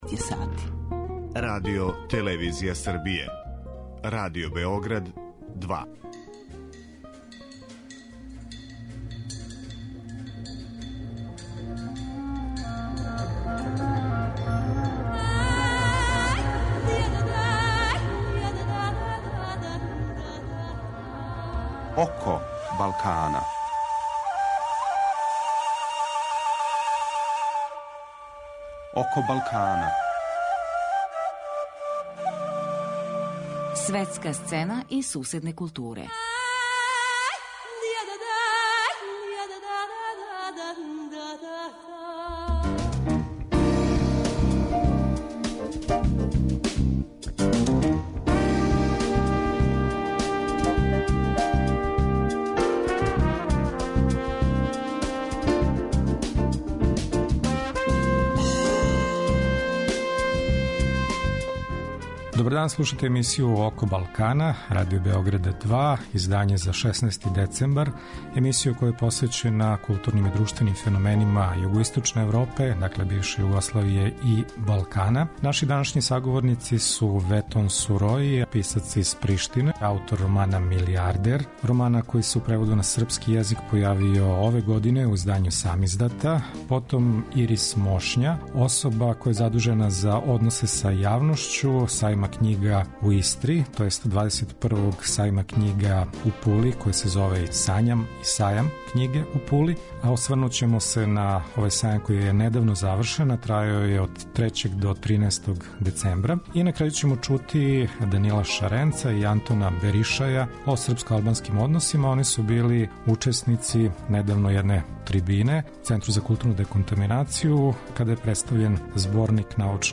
Гост емисије је косовски писац, новинар и аналитичар Ветон Сурои.